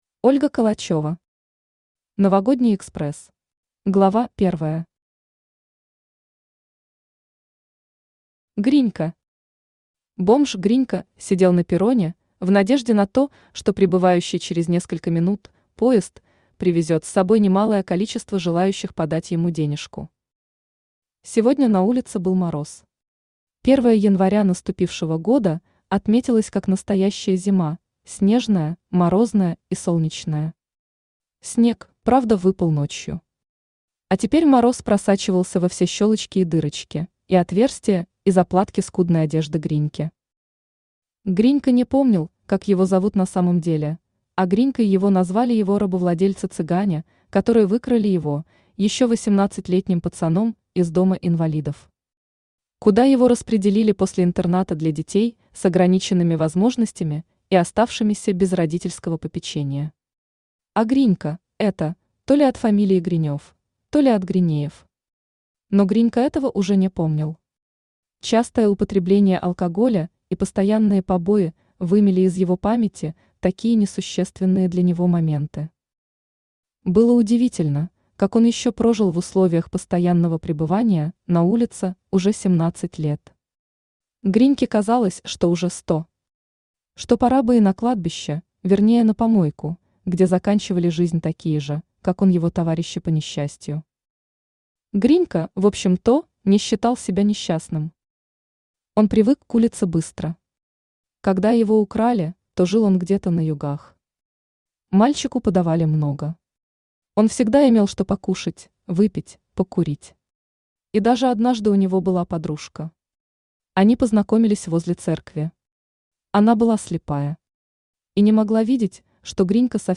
Аудиокнига Новогодний экспресс | Библиотека аудиокниг
Aудиокнига Новогодний экспресс Автор Ольга Калачева Читает аудиокнигу Авточтец ЛитРес.